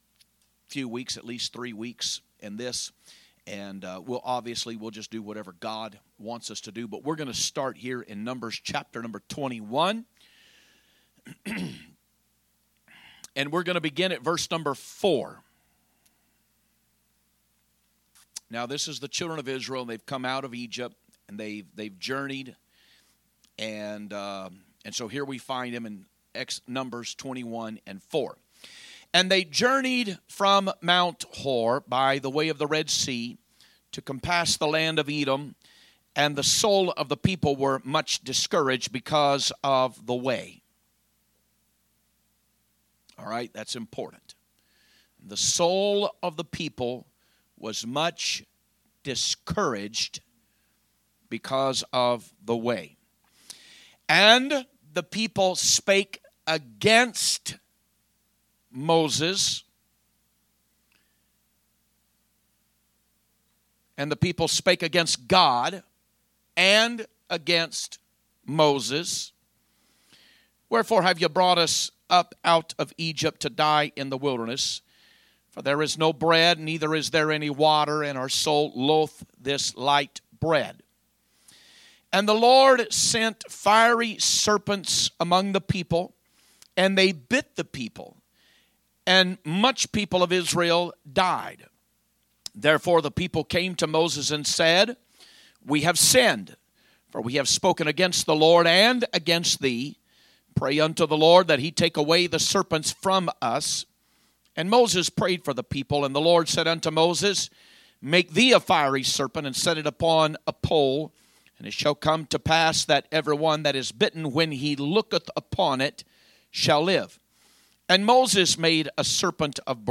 Wednesday Message